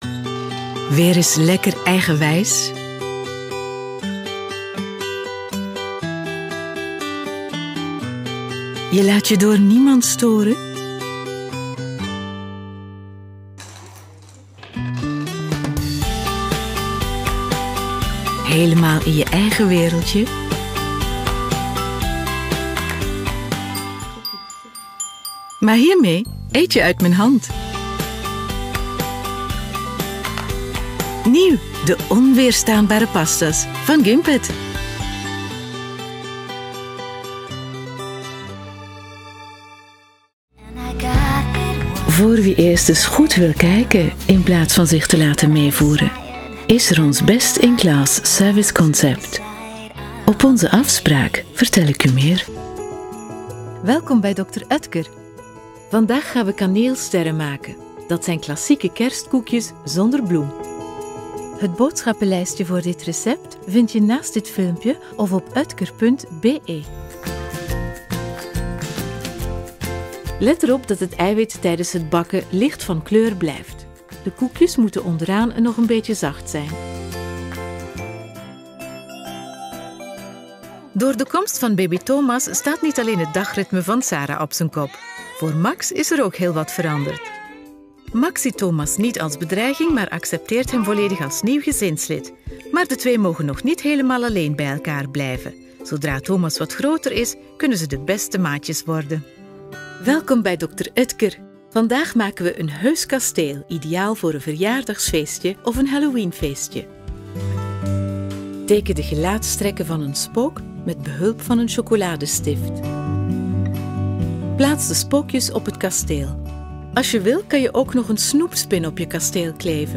Sprechprobe: Werbung (Muttersprache):
TV-Interpreter Professional voice talent Flemish and European English
Demo Flämisch_2.mp3